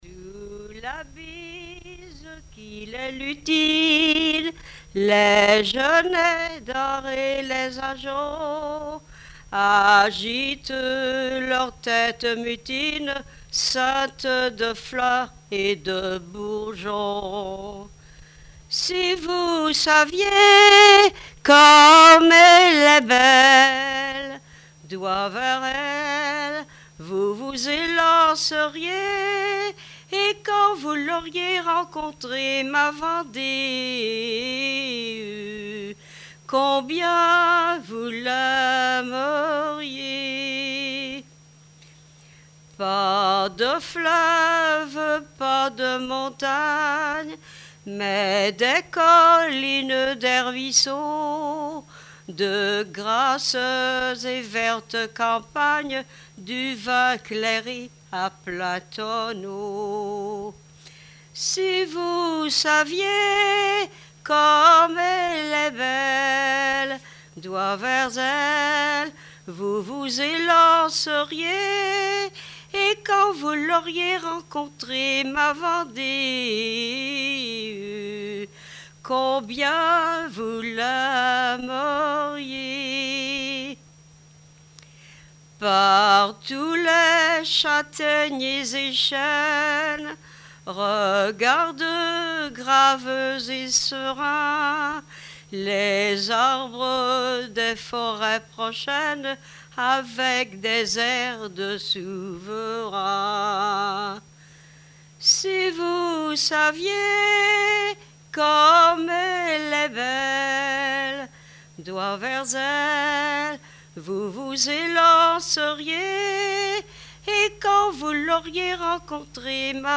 Témoignages et une chanson de music-hall
Pièce musicale inédite